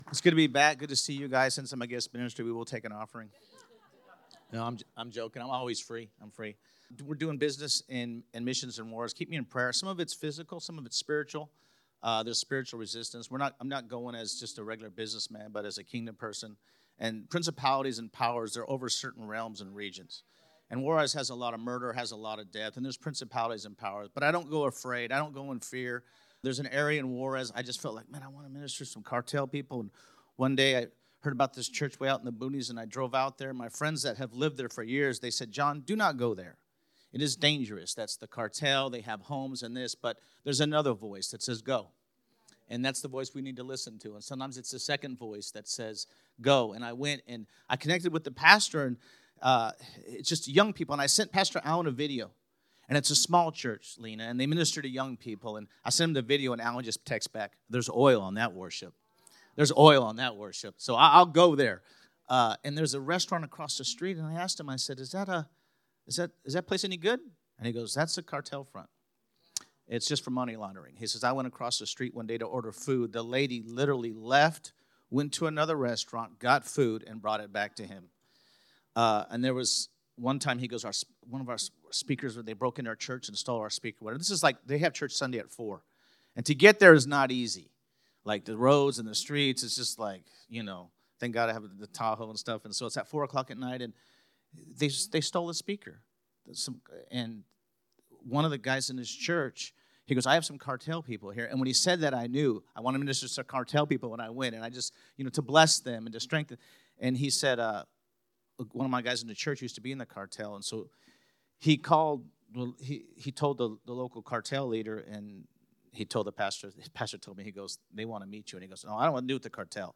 Turn The Page – SERMONS